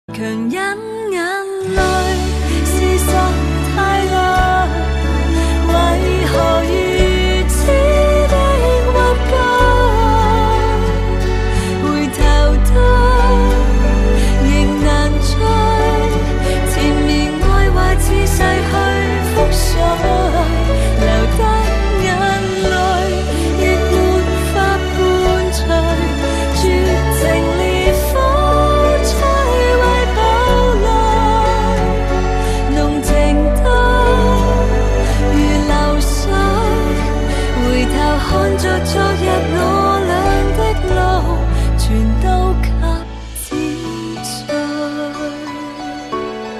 M4R铃声, MP3铃声, 华语歌曲 32 首发日期：2018-05-15 06:14 星期二